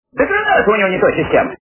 При прослушивании Белое солнце пустыни - Да гранаты у него не той системы качество понижено и присутствуют гудки.